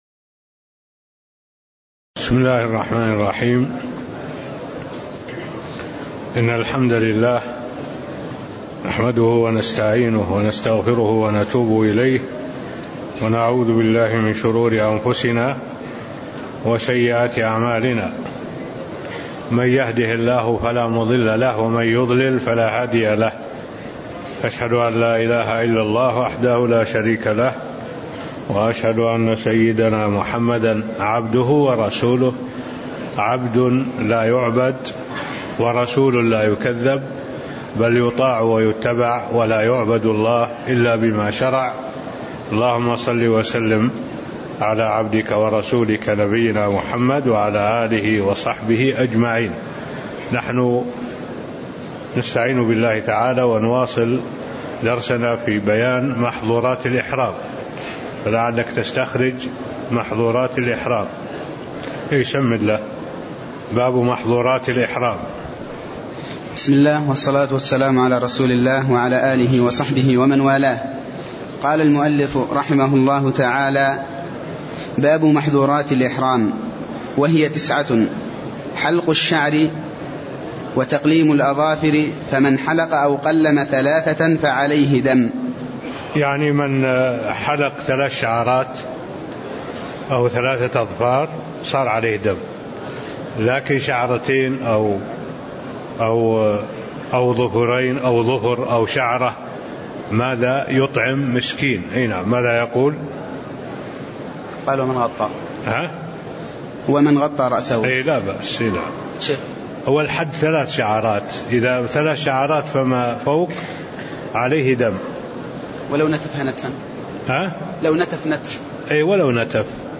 المكان: المسجد النبوي الشيخ: معالي الشيخ الدكتور صالح بن عبد الله العبود معالي الشيخ الدكتور صالح بن عبد الله العبود باب بيان محظورات الإحرام (02)